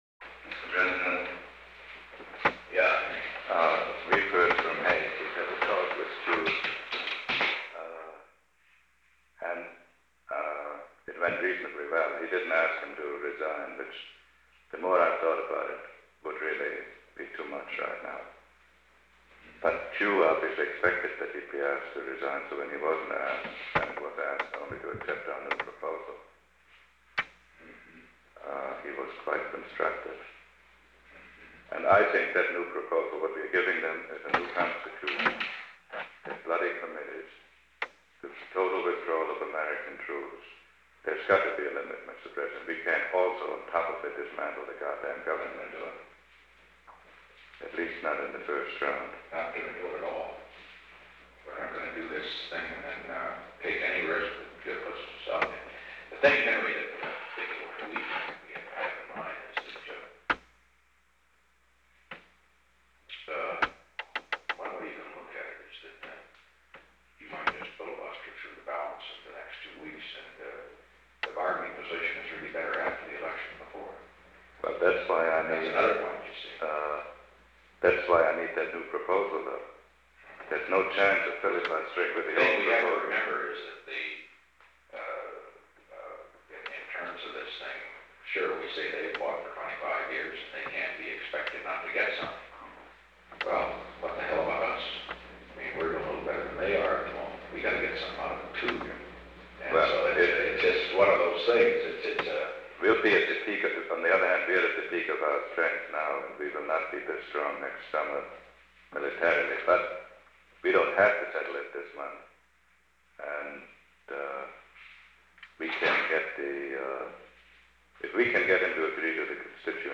Conversation: 790-004
Recording Device: Oval Office
On October 2, 1972, President Richard M. Nixon and Henry A. Kissinger met in the Oval Office of the White House from 9:38 am to 9:43 am. The Oval Office taping system captured this recording, which is known as Conversation 790-004 of the White House Tapes.